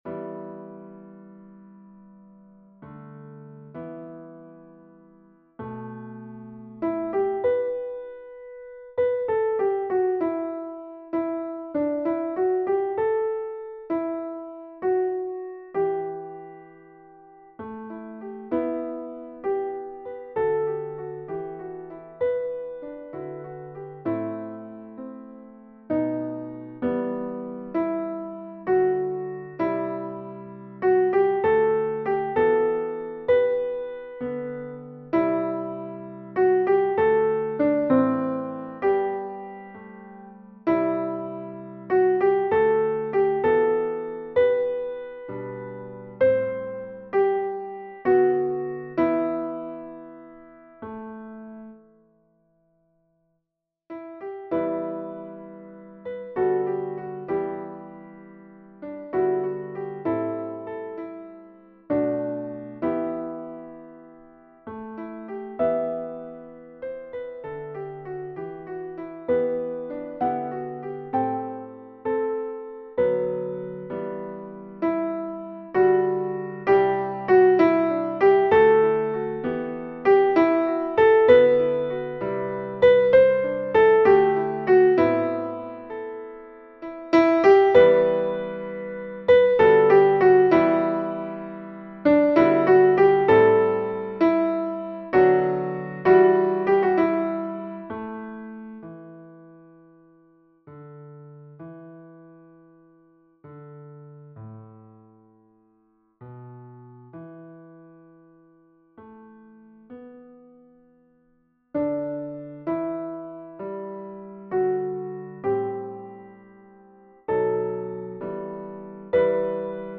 Versions "piano"
Soprano